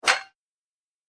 دانلود صدای دعوا 32 از ساعد نیوز با لینک مستقیم و کیفیت بالا
جلوه های صوتی